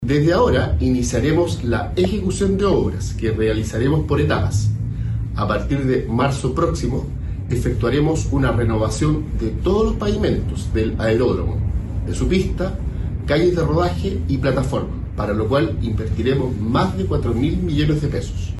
El seremi de Obras Públicas de Valparaíso, Dennys Mendoza, explicó que los trabajos se realizarán por etapas. El primero comenzará en marzo con la renovación de todo el pavimento, desde la pista, calles de rodaje y plataformas, con una inversión de más de 4 mil millones de pesos.